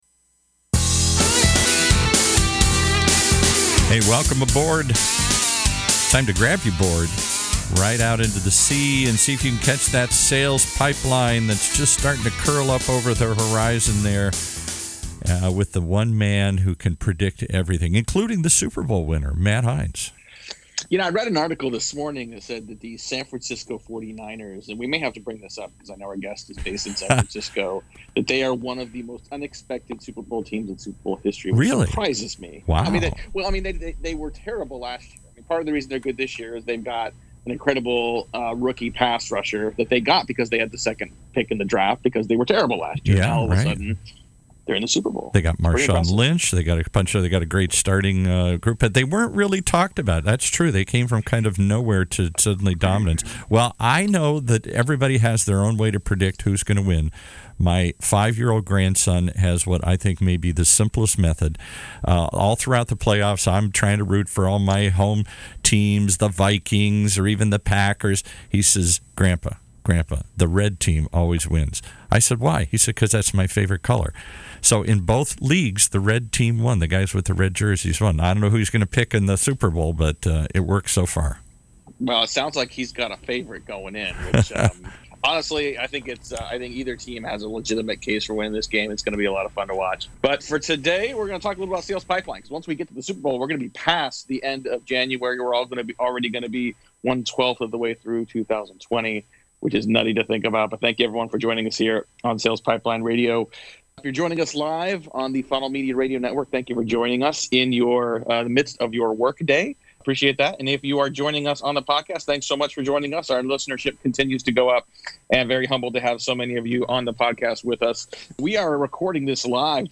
I interview the best and brightest minds in sales and Marketing.